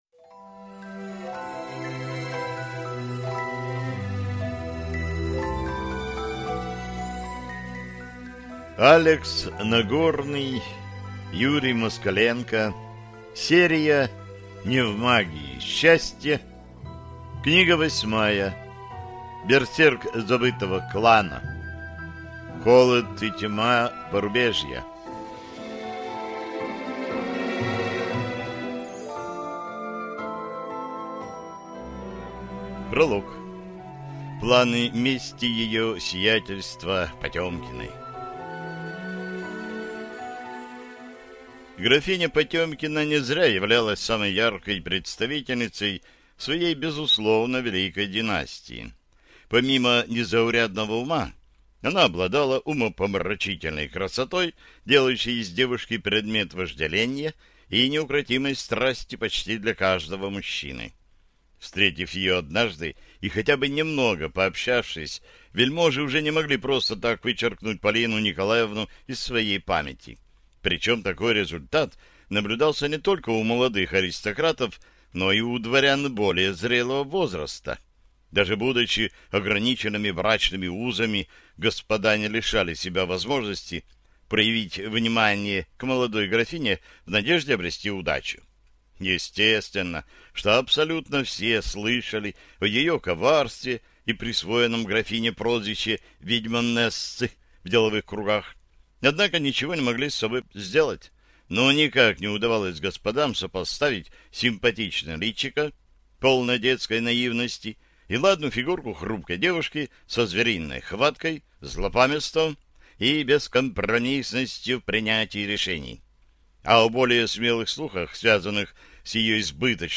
Аудиокнига Берсерк забытого клана. Холод и тьма Порубежья | Библиотека аудиокниг